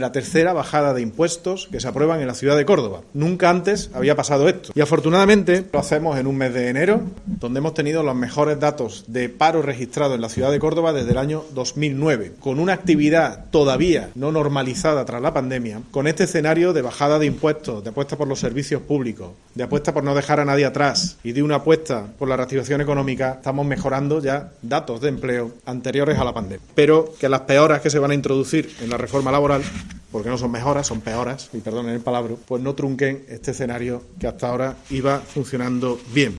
Escucha al alcalde, Jose María Bellido, sobre la aprobación de los presupuestos para 2022